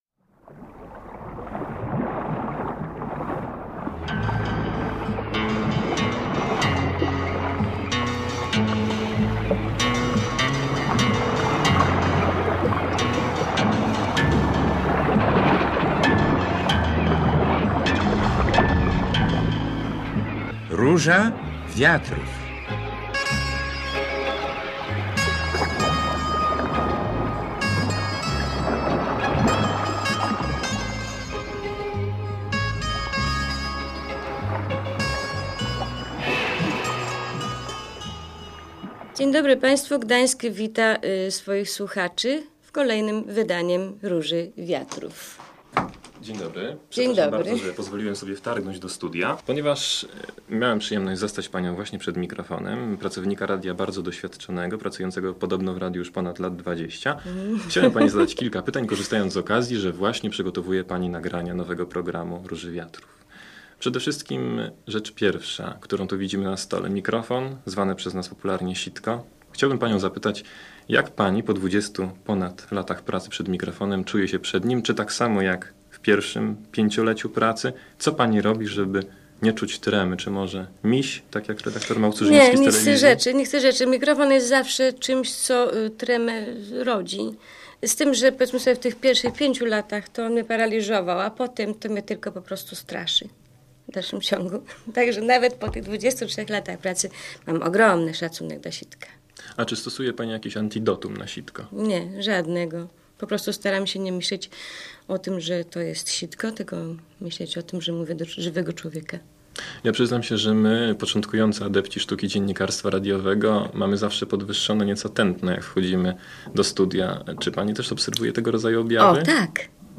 Audycja zrealizowana na zamówienie PR Gdańsk przez ekipę SAR z okazji Dnia Dziennikarza. O swojej pracy mówią reporterzy